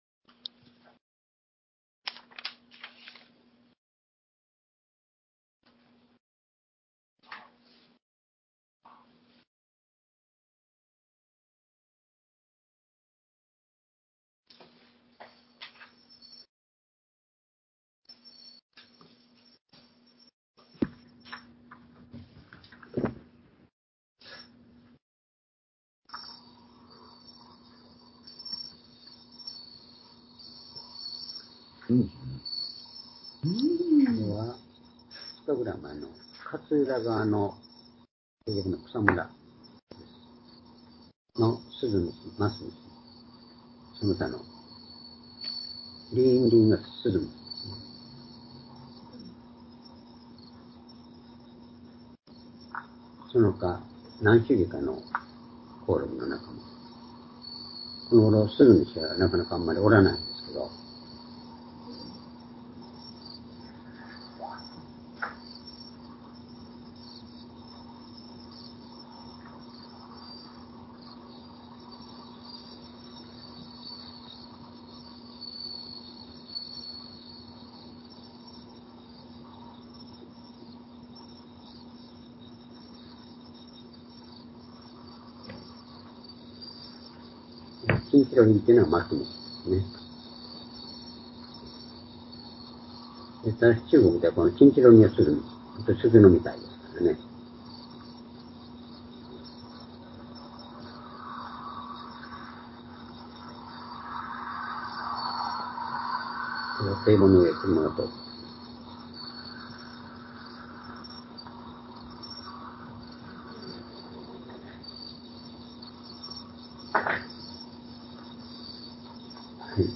「聞いてくださる神」－ヨハネ 11章41節～44節-２０２３年9月3日（主日礼拝）